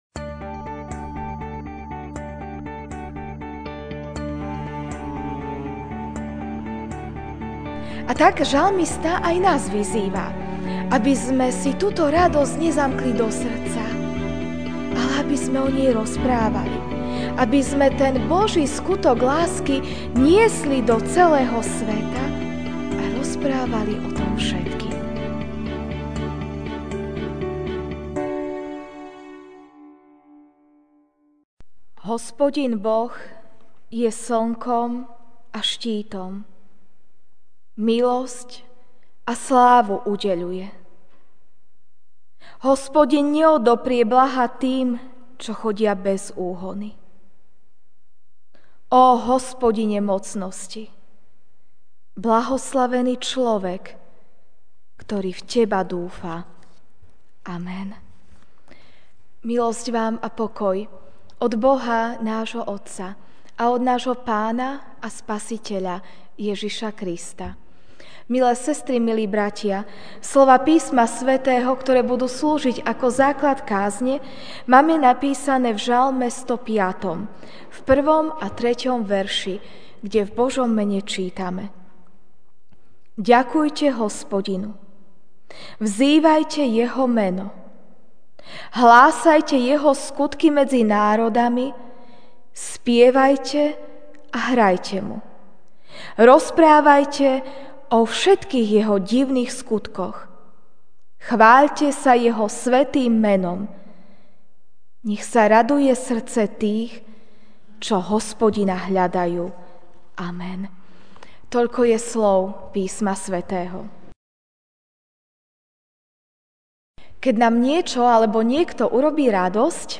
Večerná kázeň: Pochváľme sa (Žalm 105, 1-3) Ďakujte Hospodinovi, vzývajte Jeho meno!